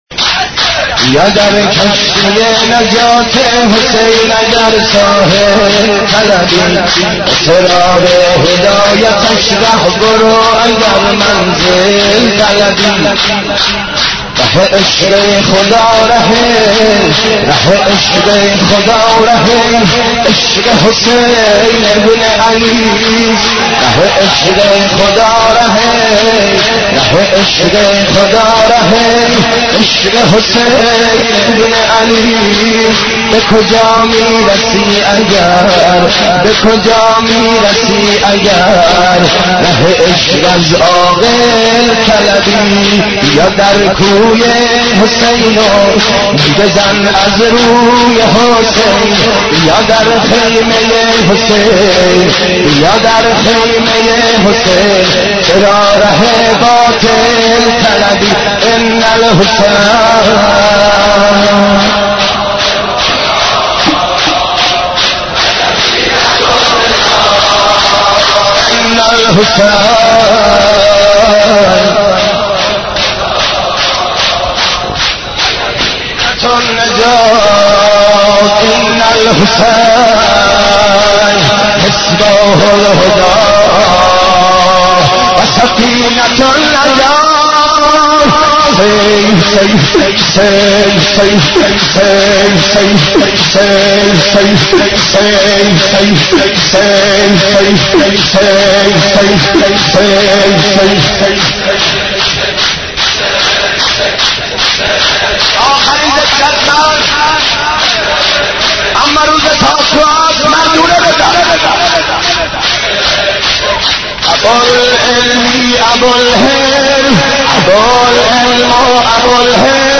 حضرت عباس ع ـ شور 34